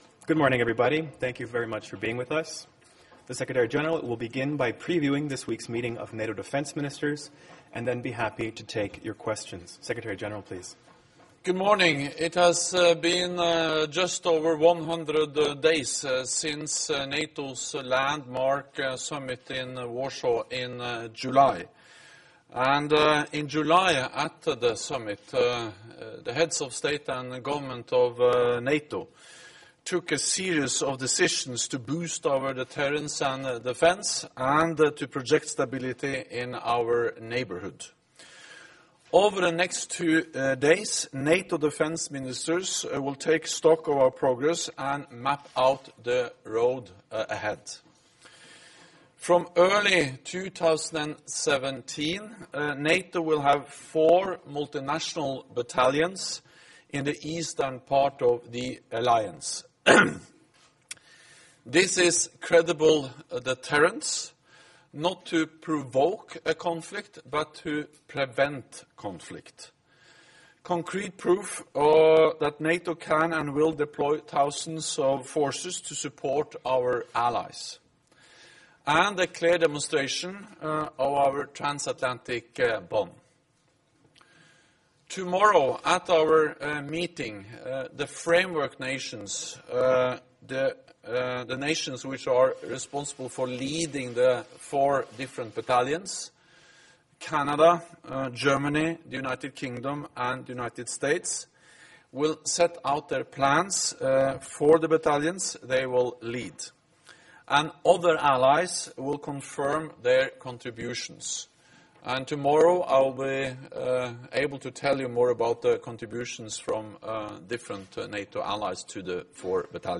Press conference by NATO Secretary General Jens Stoltenberg